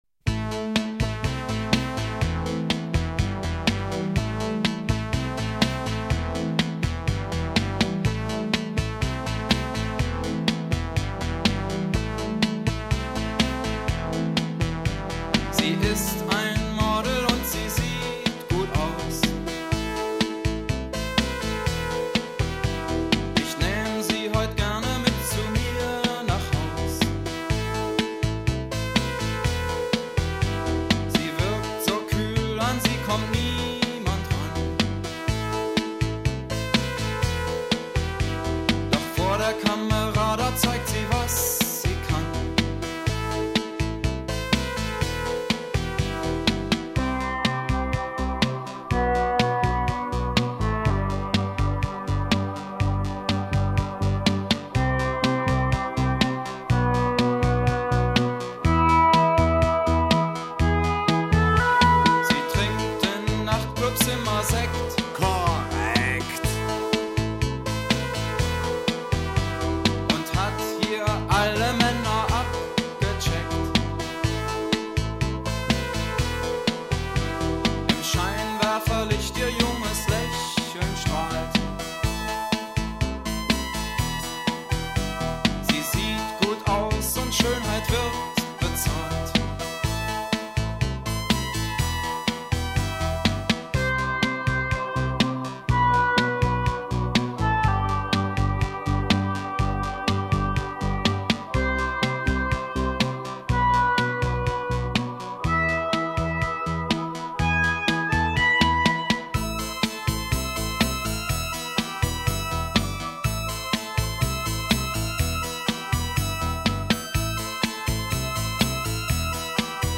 Género: Dance.